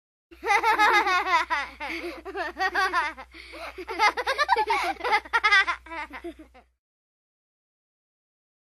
Kids Laughing Sound Effect Free Download
Kids Laughing